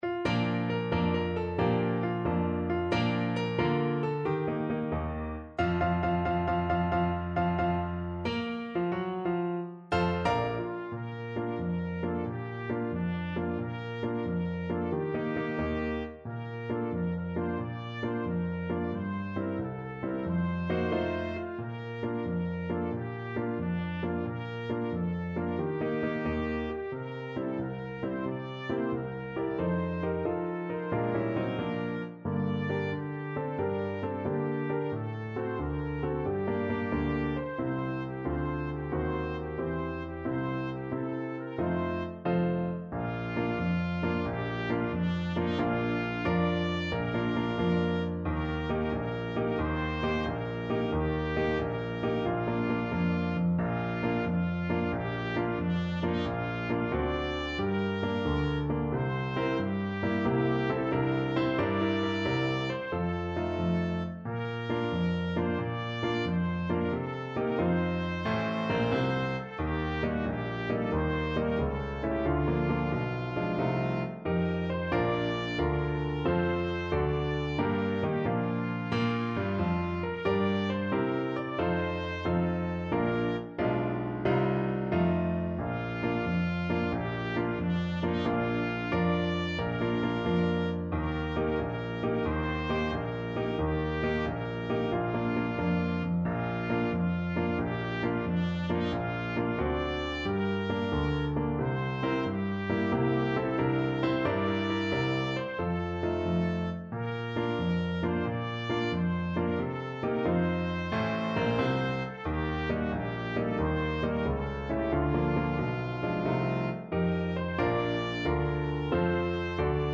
Trumpet version
6/8 (View more 6/8 Music)
Allegro .=90 (View more music marked Allegro)
Trumpet  (View more Easy Trumpet Music)
Traditional (View more Traditional Trumpet Music)